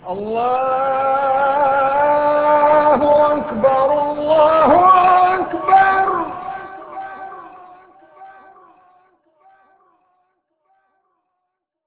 wav sound for wmmuezzin (included in callwav version)